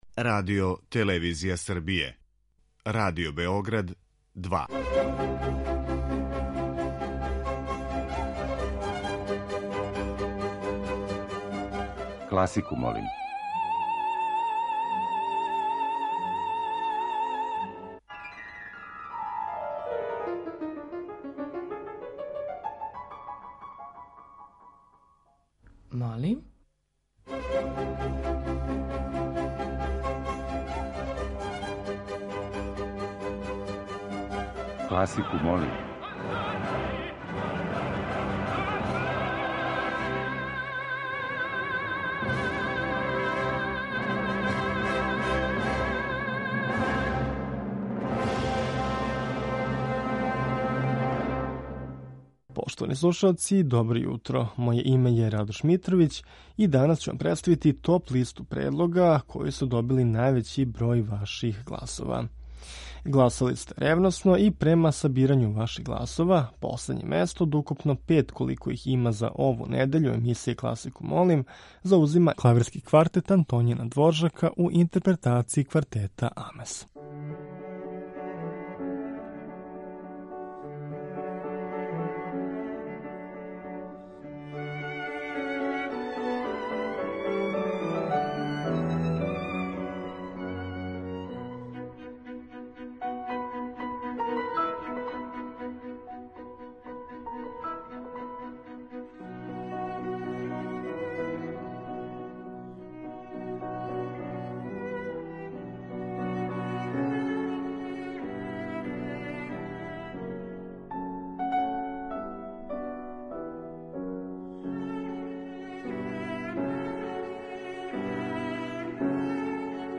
У емисији Класику, молим ове недеље окосница ће нам бити увертире познатих или мање познатих оперских остварења.